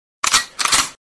loading-gun_25265.mp3